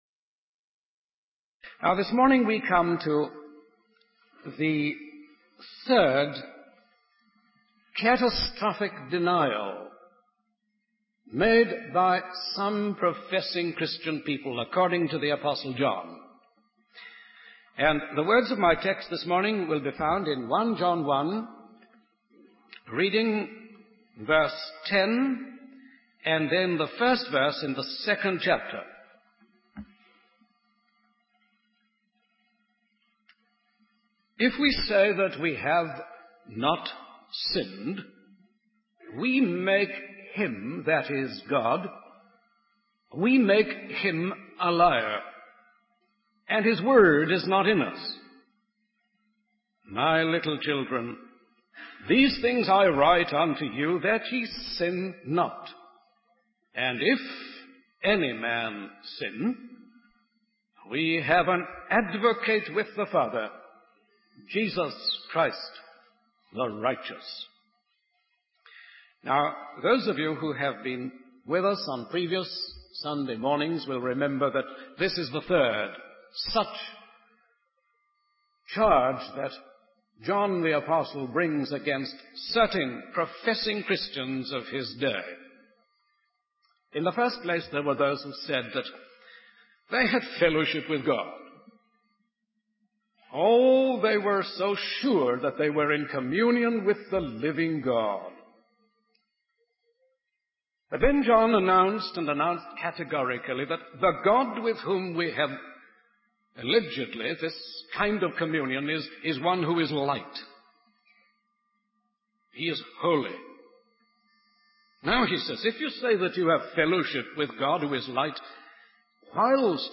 In this sermon, the speaker analyzes John's writing style and his approach to addressing sin.